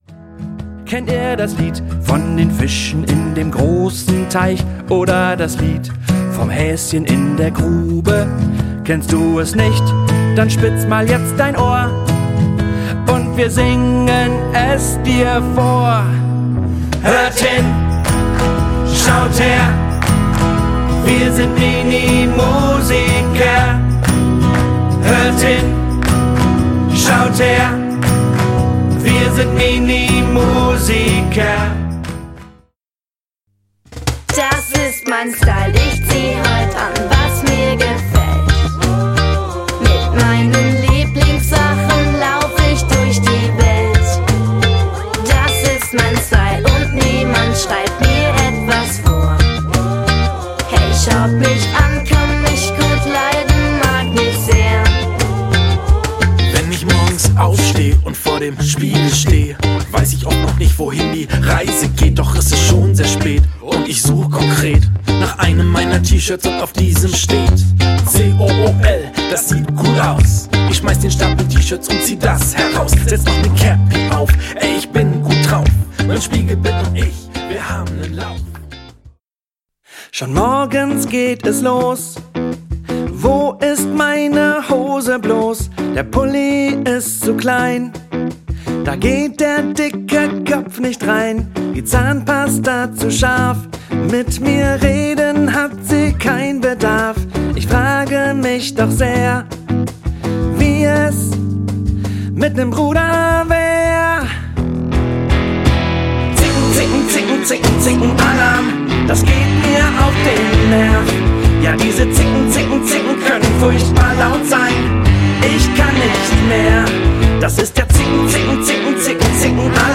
Kinder- / Jugendbuch Gedichte / Lieder